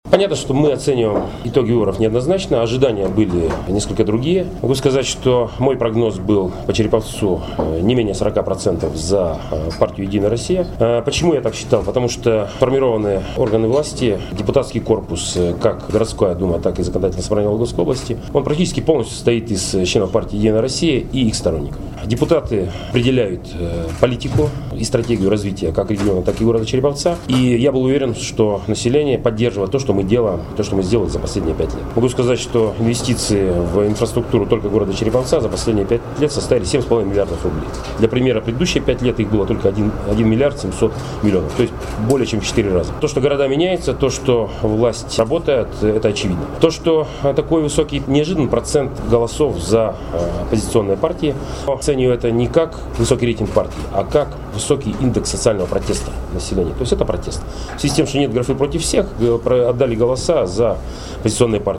«Недостаточный процент голосов вологжан за «Единую Россию» – это своего рода социальный протест», – выразил свое мнение мэр города металлургов Олег Кувшинников сегодня, 6 декабря, на медиа-завтраке с редакторами и журналистами Вологды и Череповца, сообщает ИА «СеверИнформ – Новости Череповца».